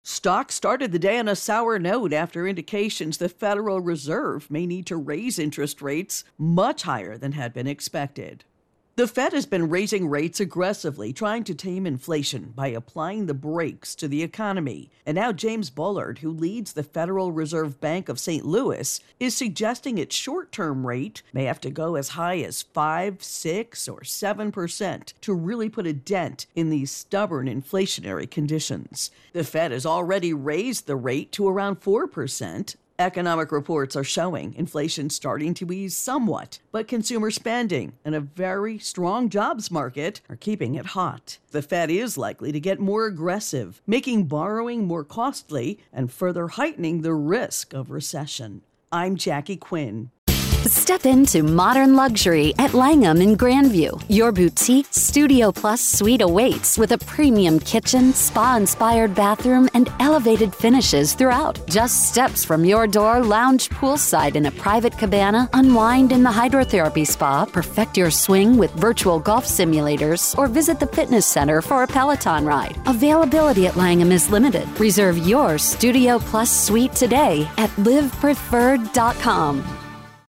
reports on Federal Reserve-Bullars.